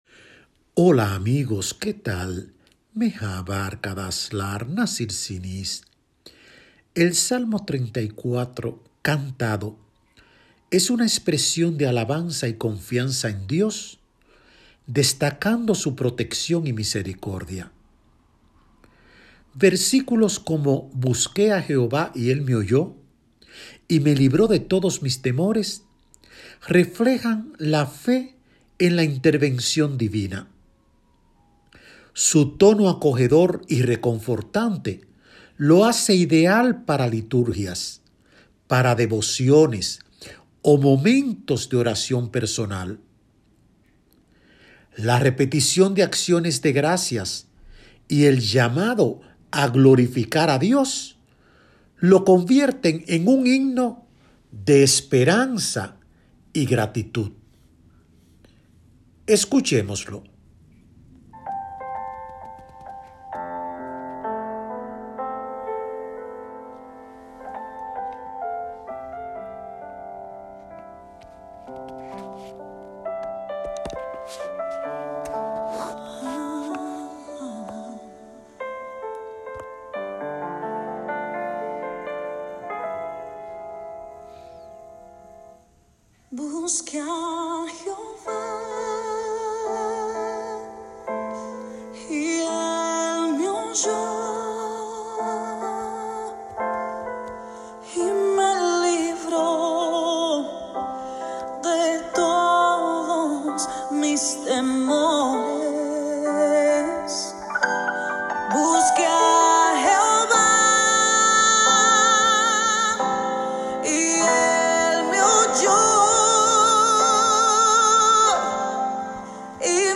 Su tono acogedor y reconfortante lo hace ideal para liturgias, devociones o momentos de oración personal. La repetición de acciones de gracias y el llamado a glorificar a Dios lo convierten en un himno de esperanza y gratitud.